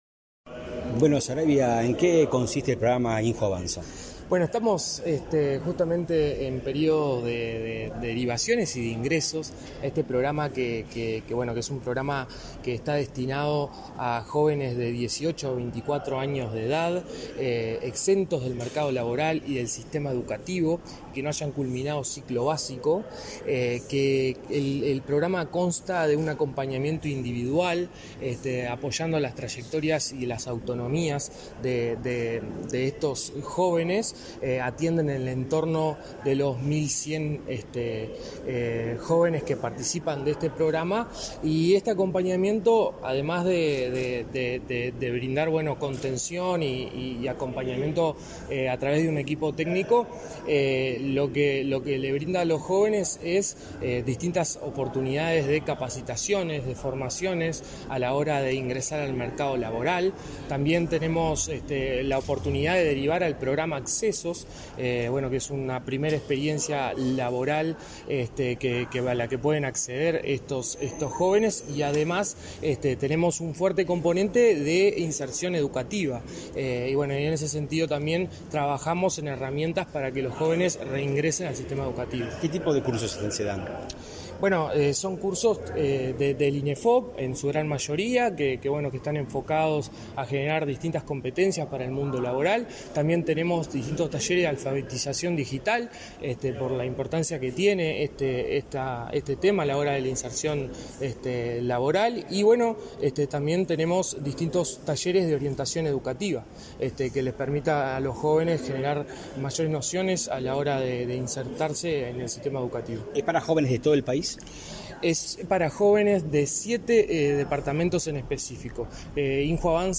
Entrevista al director del INJU, Aparicio Saravia